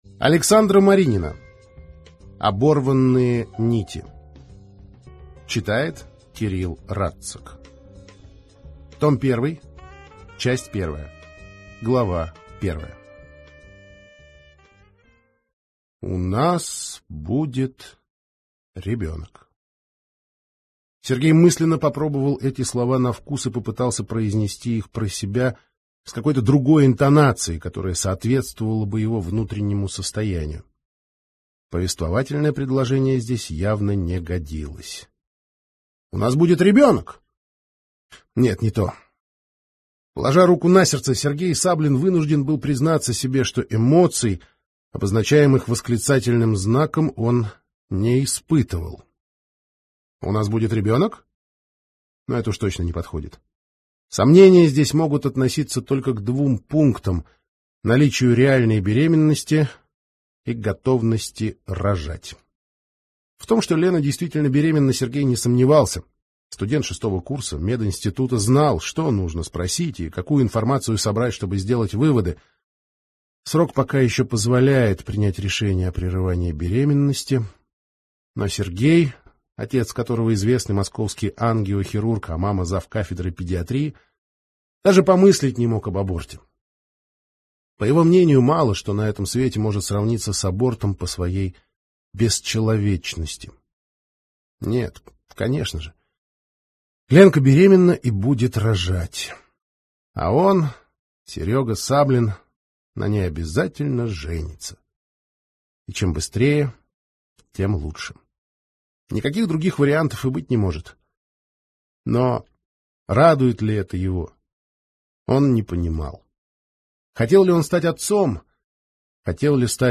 Аудиокнига Оборванные нити. Том 1 - купить, скачать и слушать онлайн | КнигоПоиск